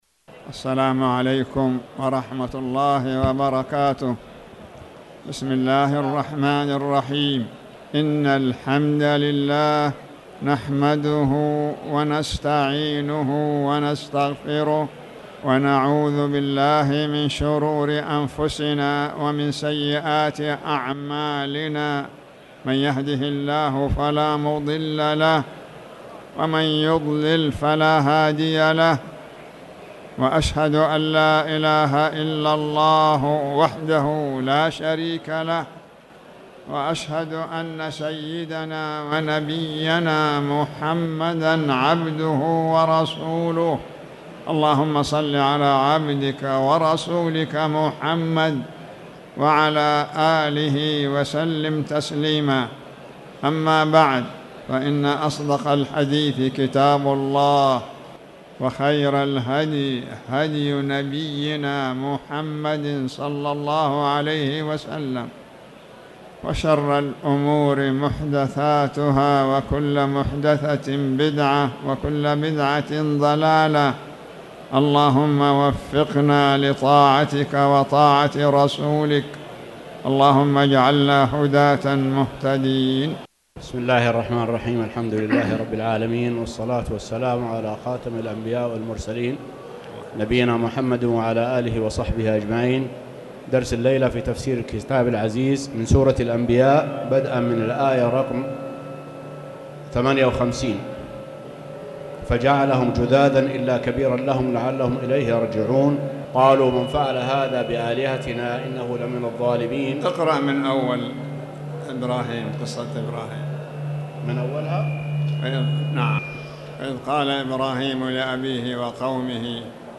تاريخ النشر ٢٦ صفر ١٤٣٩ هـ المكان: المسجد الحرام الشيخ